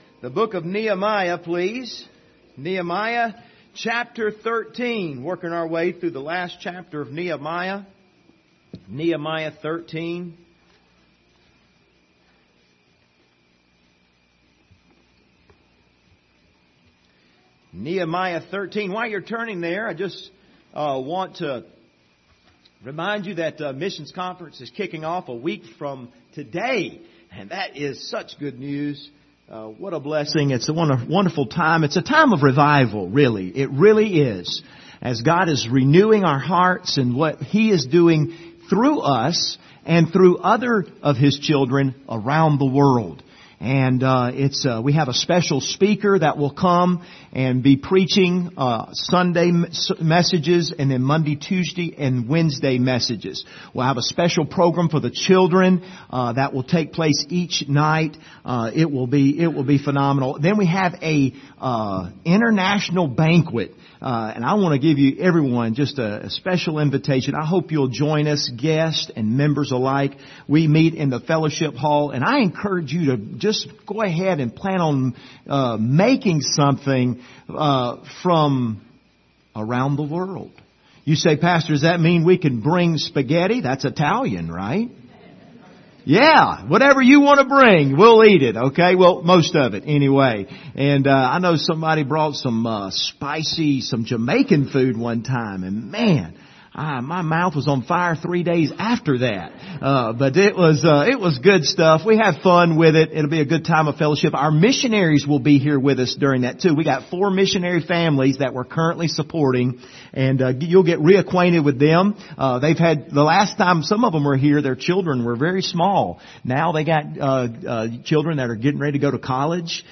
Doing a Great Work Passage: Nehemiah 13:10-14 Service Type: Sunday Morning View the video on Facebook Topics